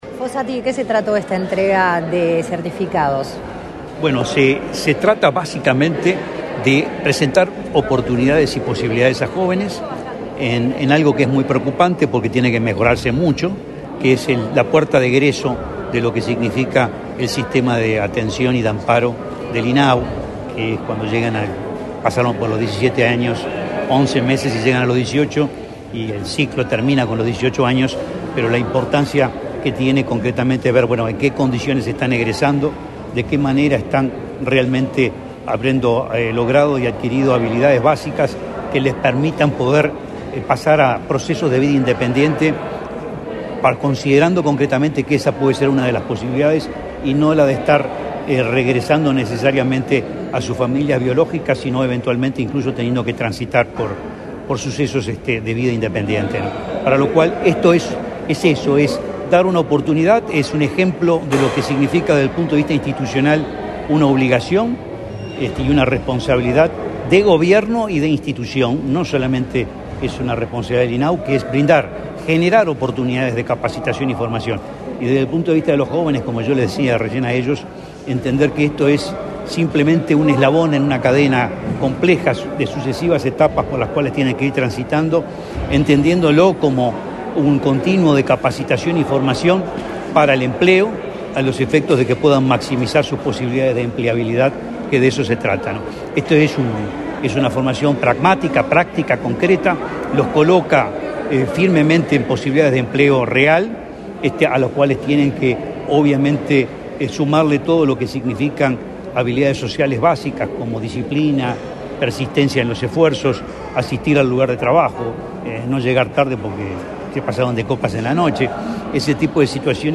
Declaraciones a la prensa del presidente del INAU, Guillermo Fossati
Tras participar en la entrega de diplomas correspondientes al curso de Medio Oficial Gomero, de capacitación dirigida a adolescentes de hasta 18 años